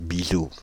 Bizou (French pronunciation: [bizu]
Fr-Bizou.ogg.mp3